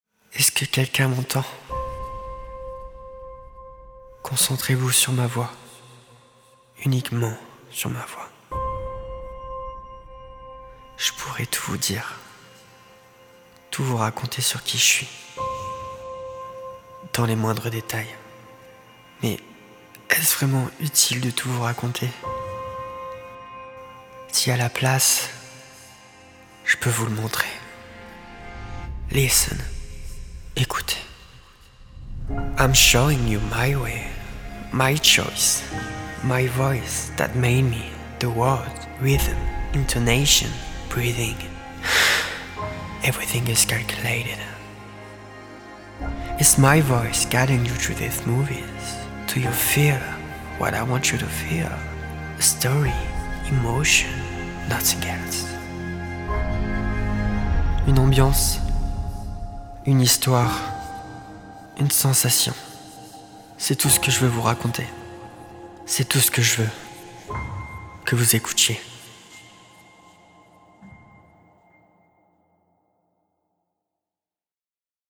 Voix off
15 - 30 ans - Contre-ténor